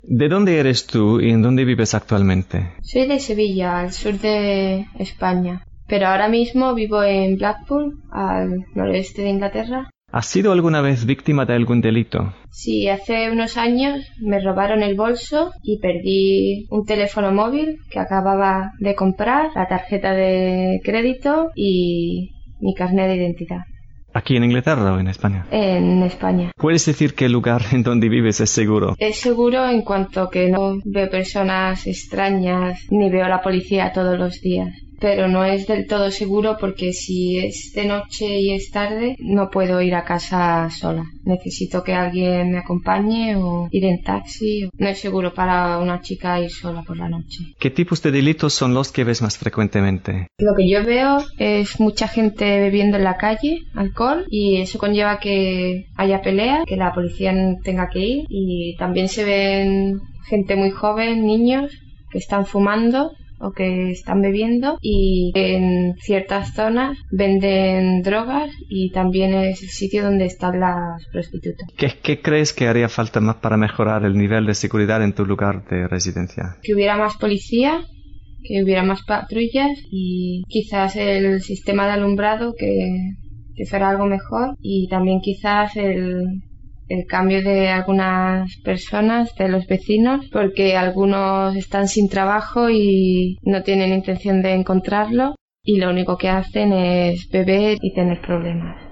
La Seguridad: Entrevista #1
(Safety: Interview #1)
Recording: 0022 Level: Advanced Spanish Variety: Spanish from Spain